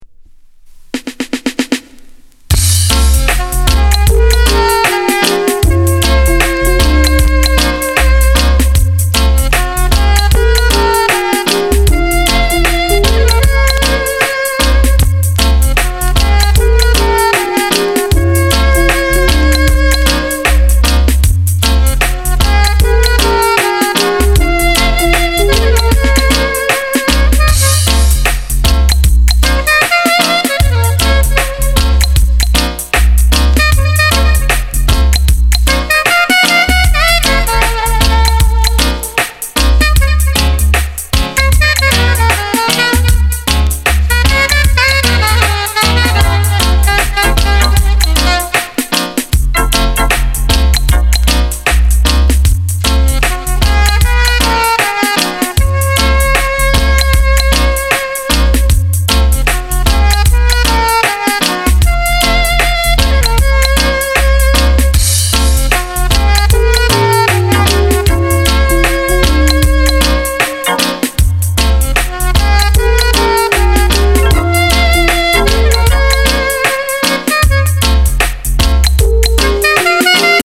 Genre: Reggae/Lovers Rock